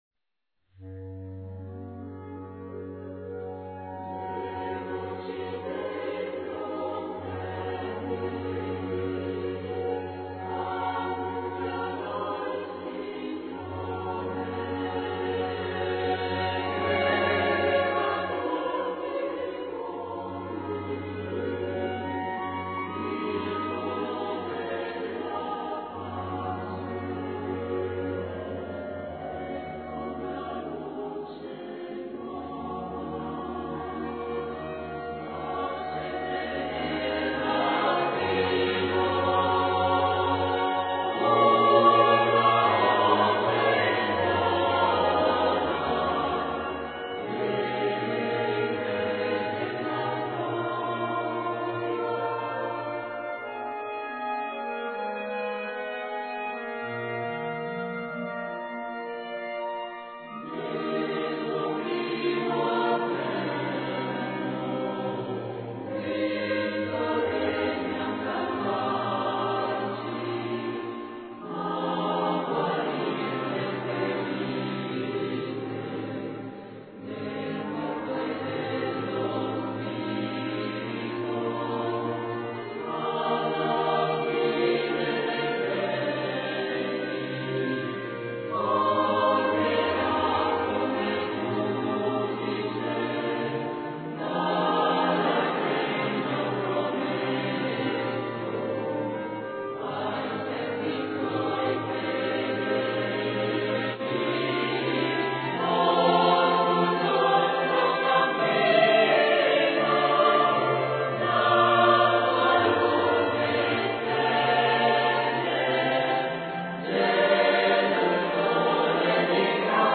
Per coro e pianoforte